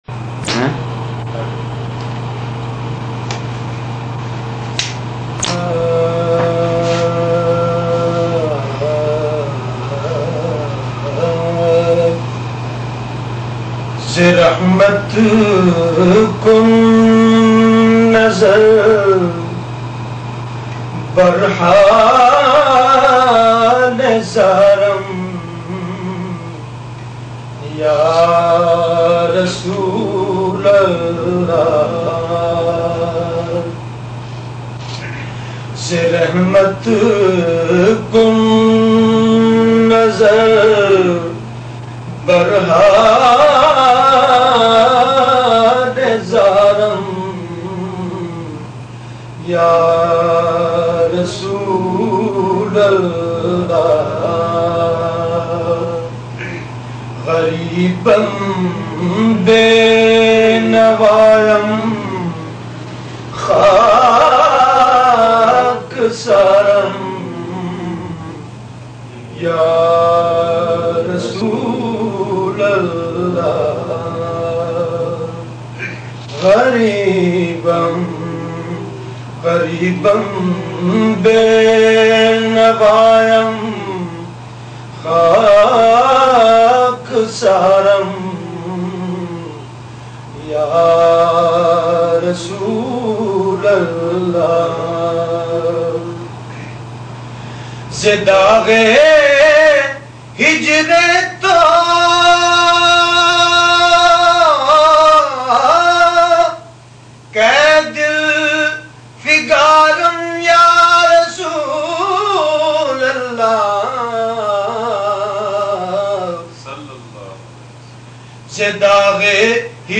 Farsi Naat